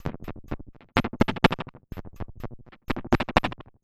tx_perc_125_yuckbeat2.wav